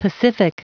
Prononciation du mot pacific en anglais (fichier audio)